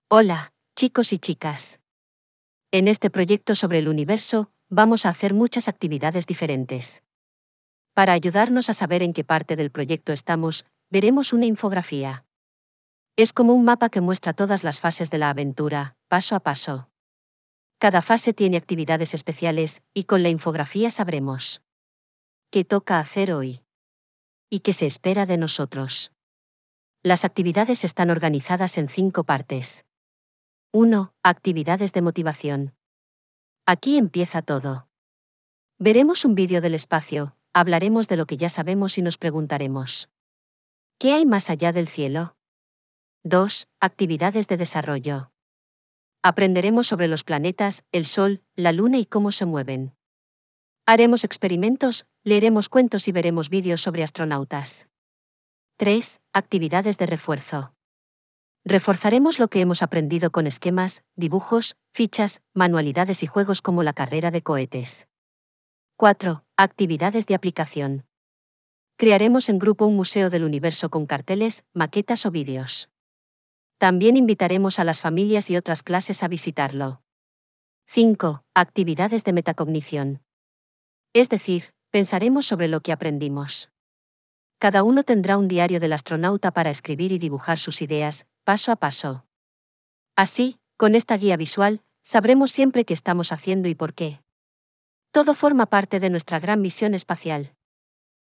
Lectura facilitada
Text_to_Speech_4.wav